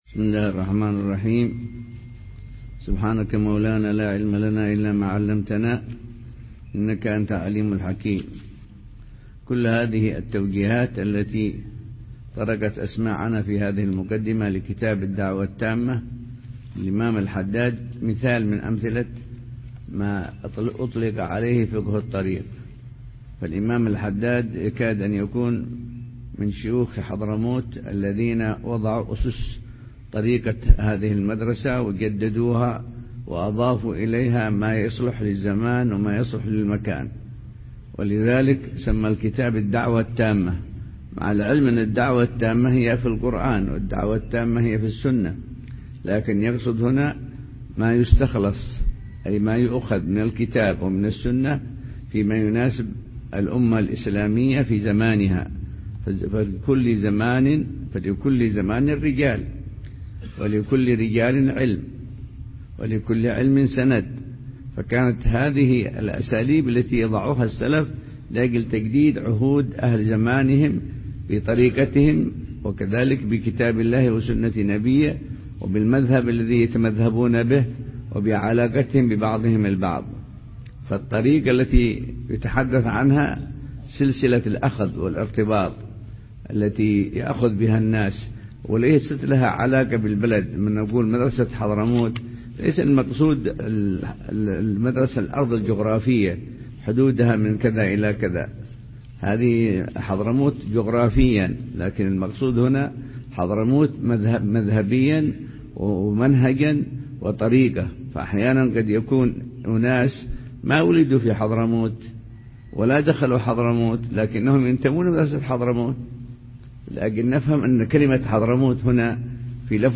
فقه الطريق – الدرس السادس سلسلة مجالس الفجر للحبيب أبوبكر المشهور بمسجد الجوهري بمدينة تريم – حضرموت صباح الخميس 7 ربيع الثاني 1438هـ